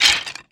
horror
Skeleton Attack Bone Rattle 2